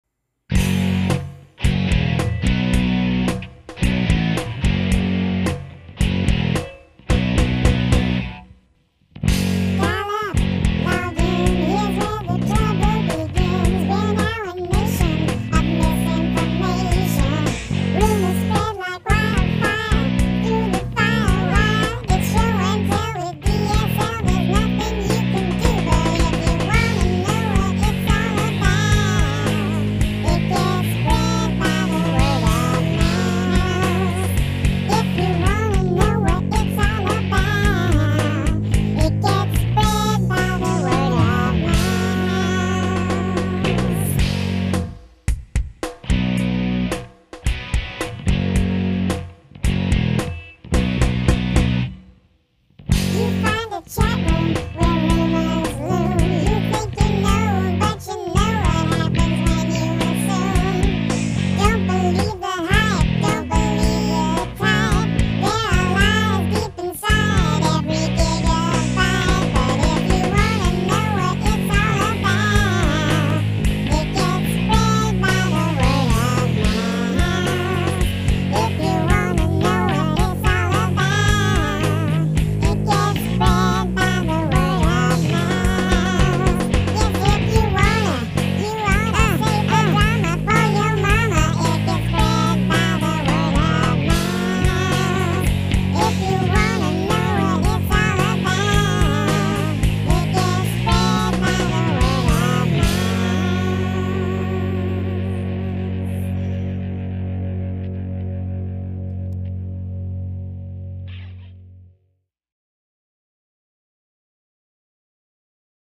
Their story is joyous, yet poignant, as is their music.